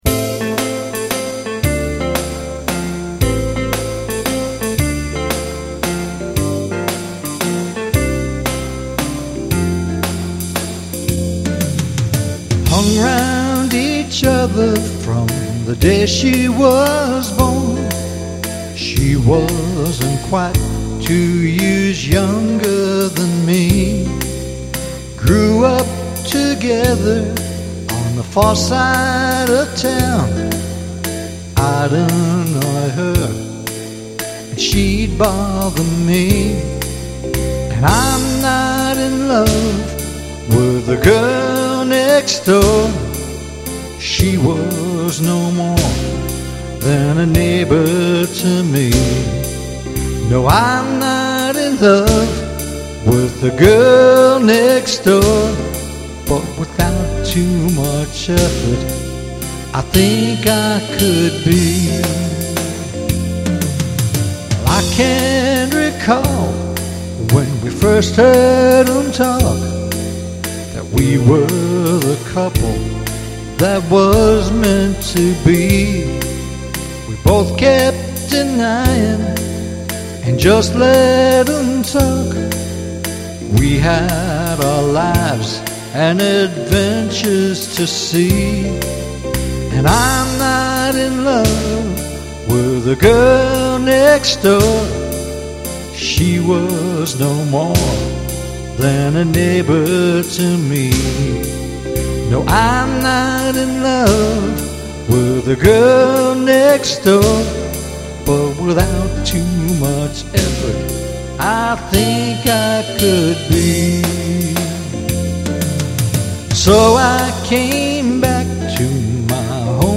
August '08 Original Song Demo
written this past January, and I sequenced all the parts the same day.
Here's the Lyrics: Key of F if you're playing along!